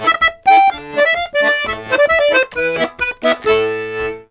Index of /90_sSampleCDs/USB Soundscan vol.40 - Complete Accordions [AKAI] 1CD/Partition C/04-130POLKA
C130POLKA1-L.wav